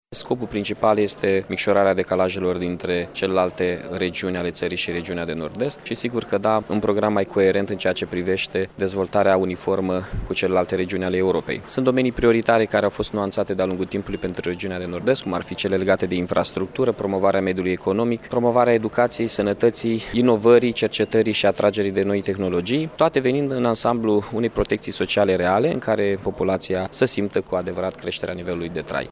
Primarul Iaşului, Mihai Chirica, şi-a exprimat speranţa că o dată cu înfiinţarea acestei asociaţii, vor fi micşorate decalajele de dezvoltare dintre localităţile din Moldova şi restul ţării.